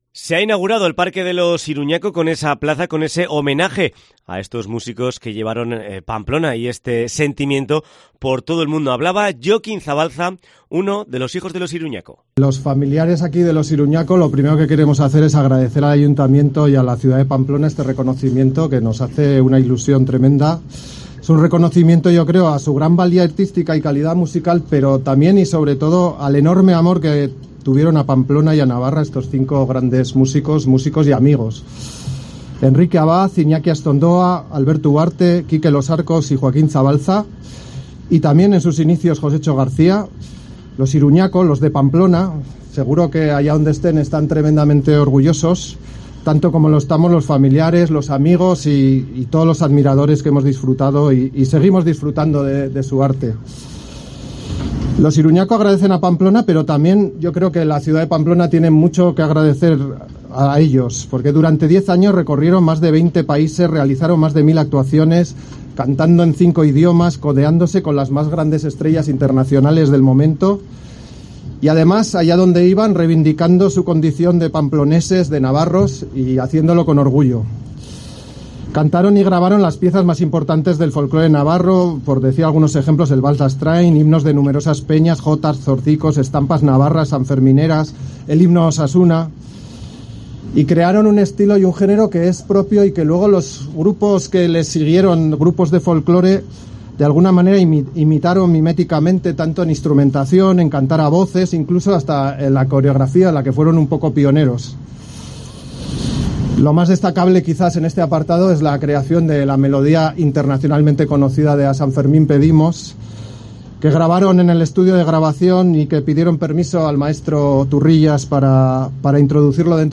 En un acto de homenaje a los Iruña'ko no podía faltar la música